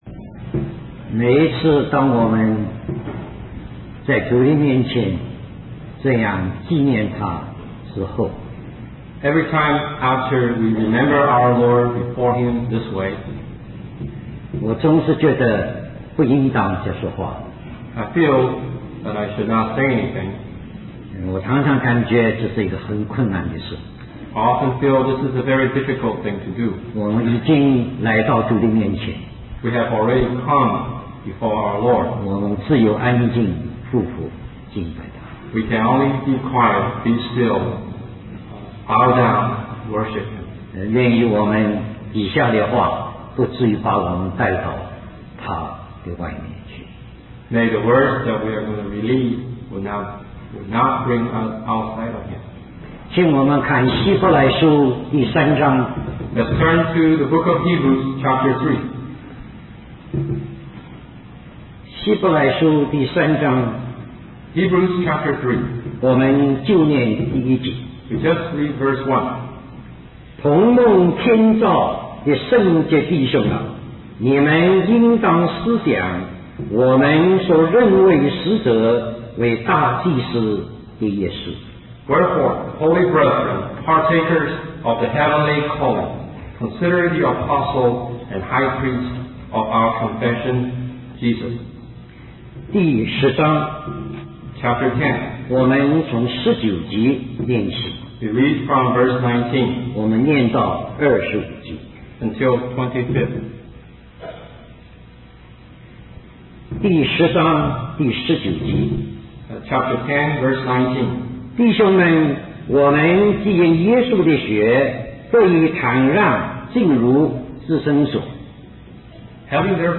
He stresses the importance of community and mutual encouragement among believers as they await the return of Christ. Ultimately, the sermon calls for a deeper relationship with Jesus, who is the true substance of faith.